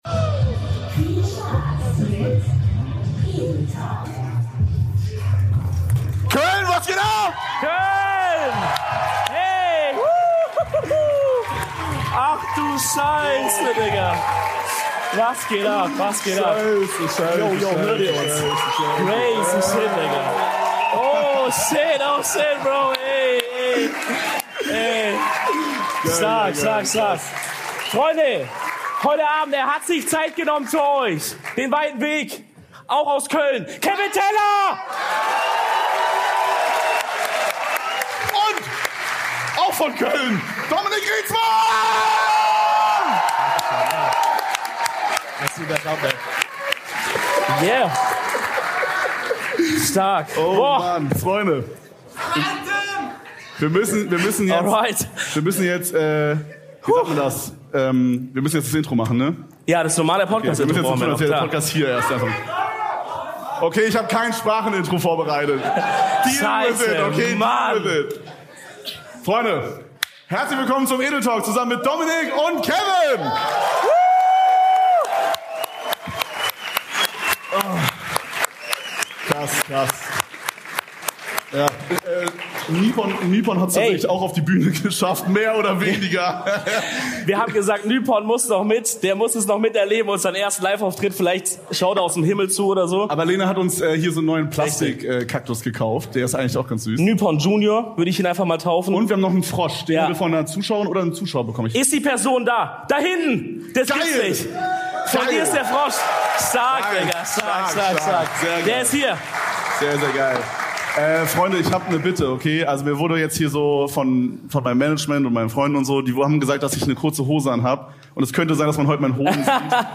UNSER ERSTER LIVE AUFTRITT!
LIVE AUS KÖLN